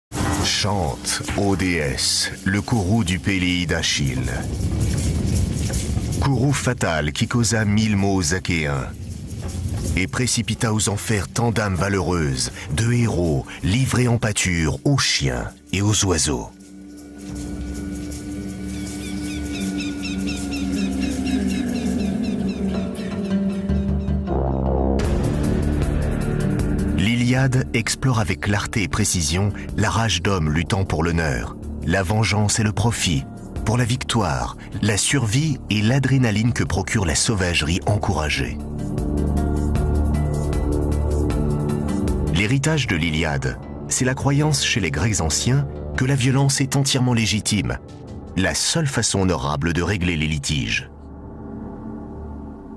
Prestation voix-off virile et poétique pour "Ancient Worlds" : un ton convaincant et dramatique
Voix convaincante et élégante.
Dans ce documentaire, j’ai utilisé une tonalité de voix grave pour correspondre au thème sérieux et historique de l’émission.
Pour « Ancient Worlds », j’ai dû adopter un ton viril et dramatique, pour refléter la gravité et la majesté des civilisations passées.